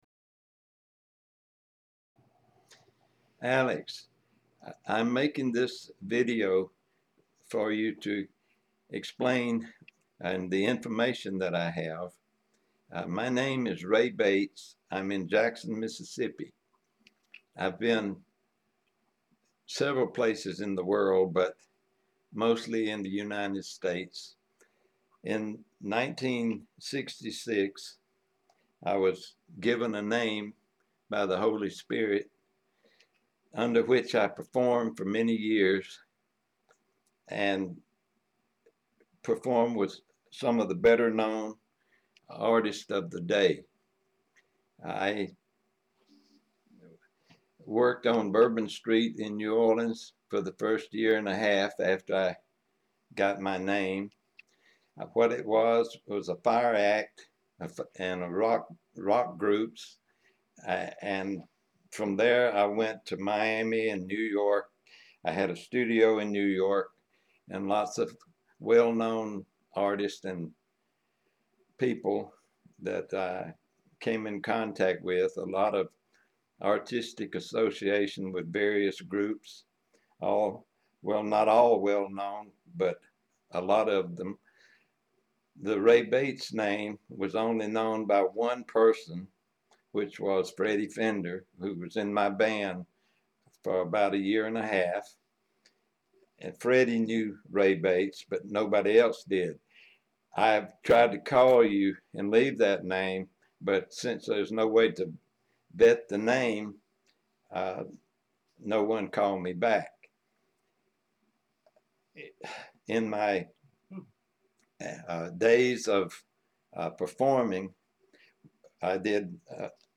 Bio and interview